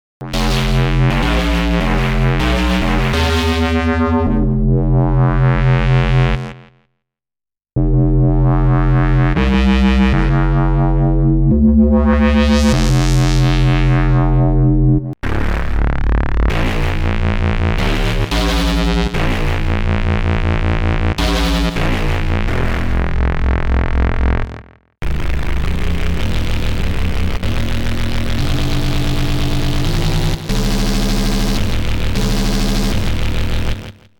If yes, Sy Tone got the wobble build-in and more :wink: